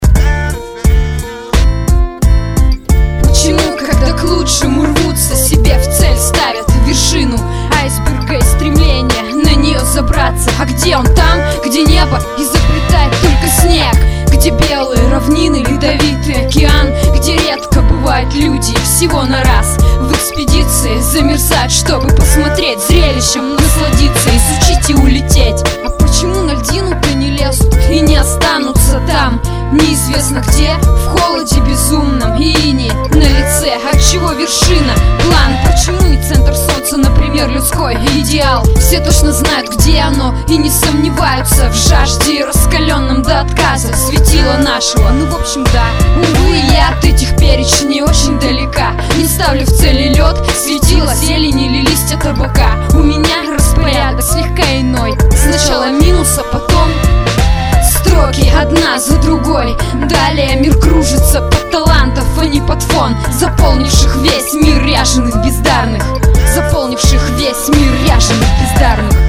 • Жанр: Рэп